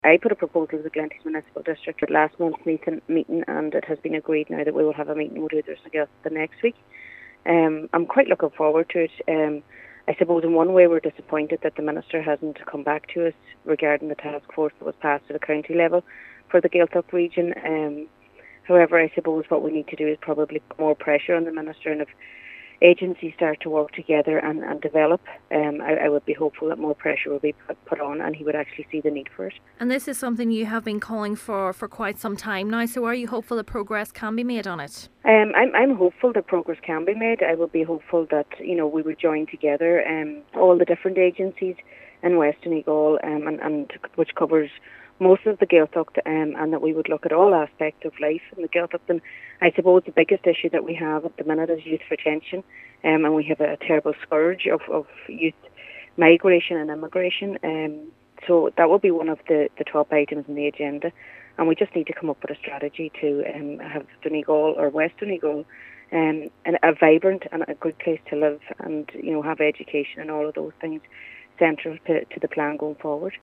Councillor Gallagher says there has been a surge in youth migration and immigration and that is something that will be high on the agenda next week: